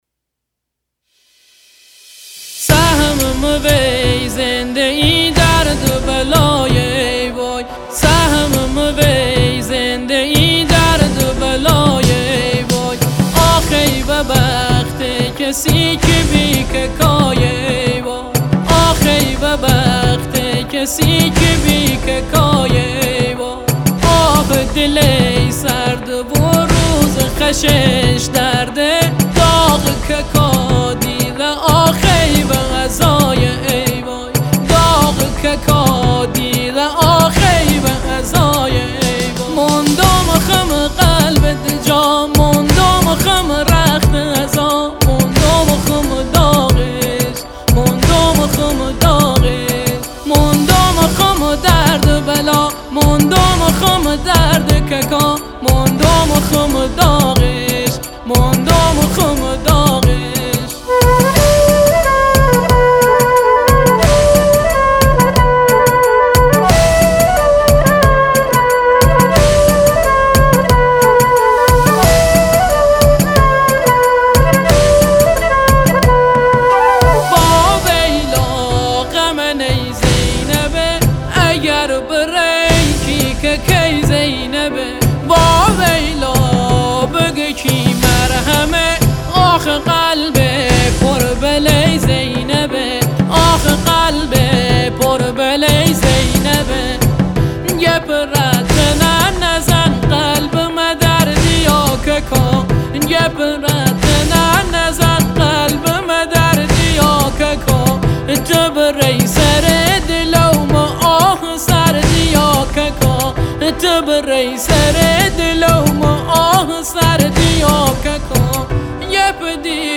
مداحی و نوحه لری